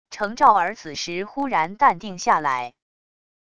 程兆儿此时忽然淡定下来wav音频生成系统WAV Audio Player